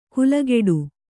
♪ kulageḍi